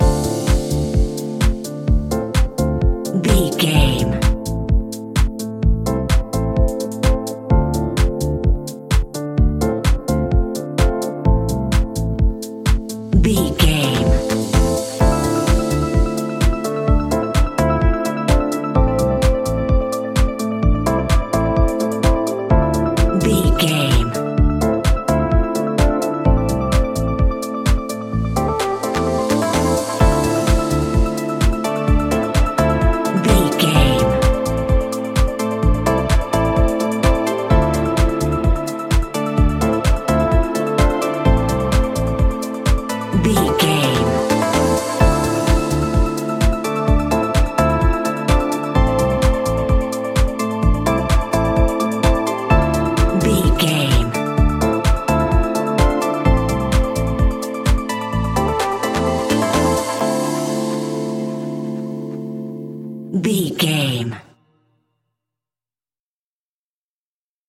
Aeolian/Minor
groovy
uplifting
energetic
drums
drum machine
synthesiser
electric piano
bass guitar
funky house
disco
upbeat
instrumentals